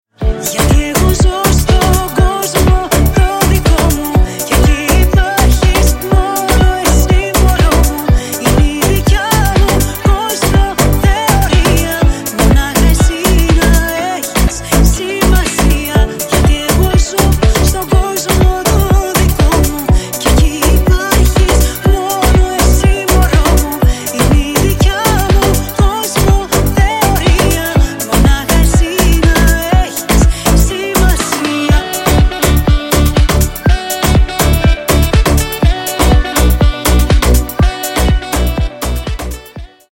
Клубные Рингтоны
Поп Рингтоны